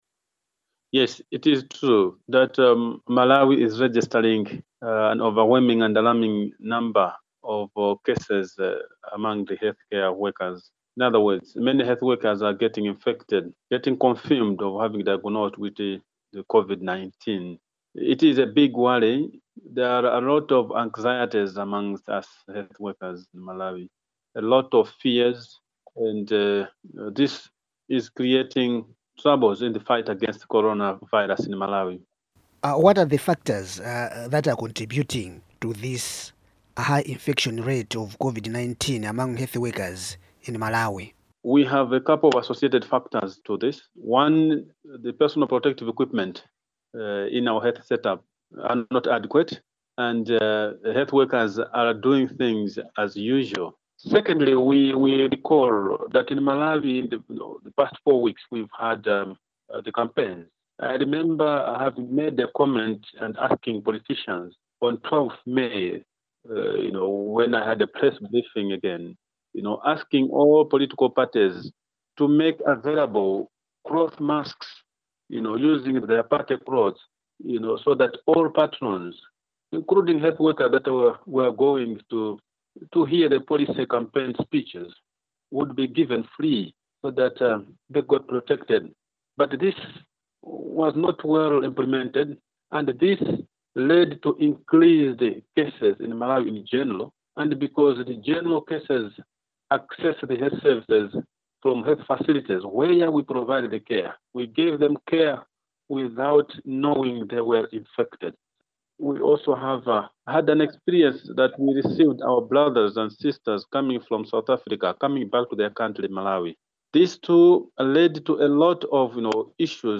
Malawi’s health ministry says 223 health workers have tested positive for COVID-19. For Africa News Tonight, reporter